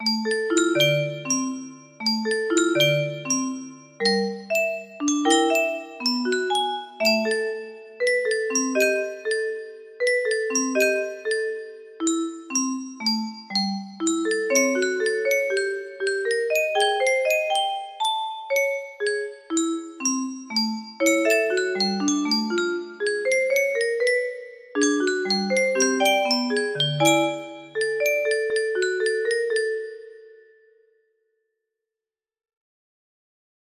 Feather Fluffing music box melody